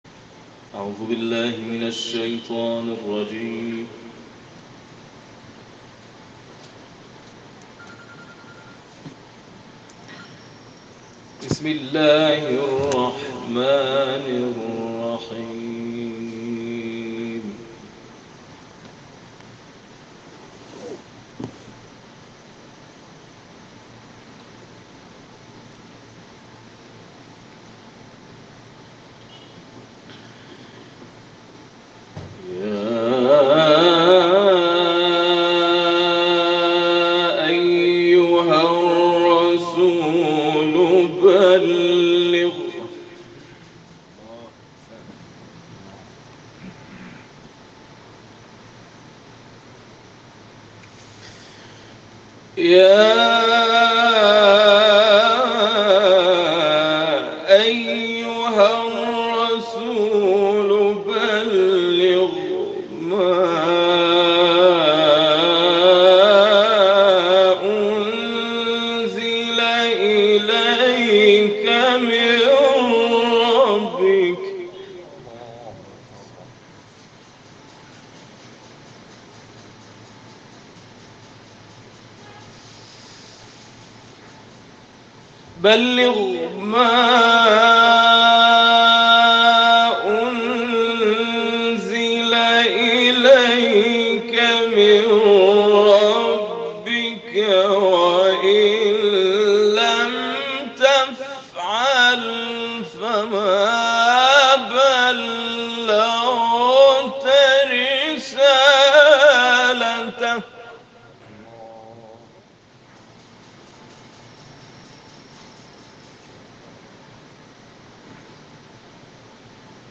مراسم جشن عید سعیدغدیرخم با حضور گروهی از کاروان قرآنی اعزامی به حج تمتع(کاروان نور)، شنبه، 25 تیرماه در نمازخانه بیمارستان مرکز پزشکی حج و زیارت جمعیت هلال احمر در مکه مکرمه برگزار شد.